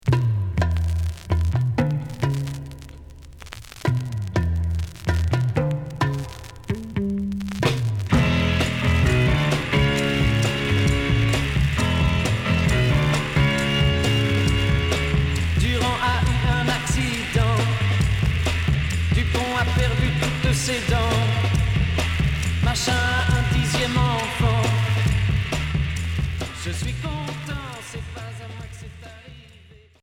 Garage 60's